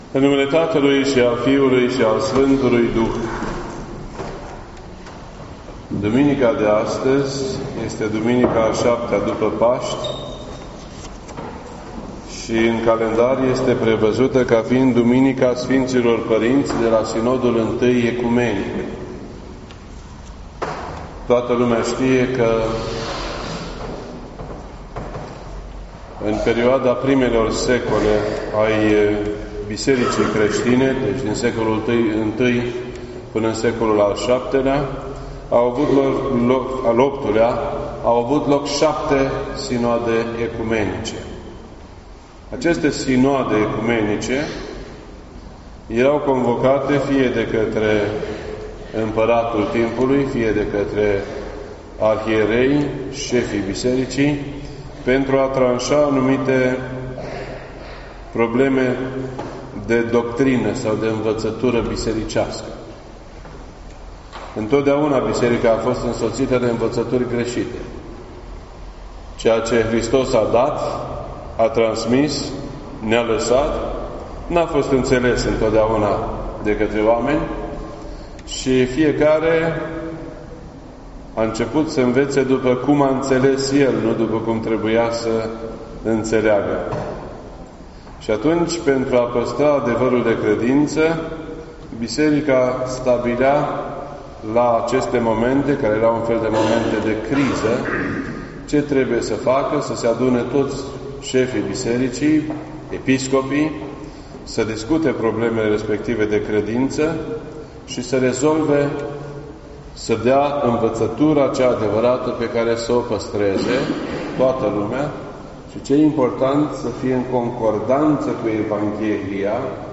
This entry was posted on Sunday, June 9th, 2019 at 7:38 PM and is filed under Predici ortodoxe in format audio.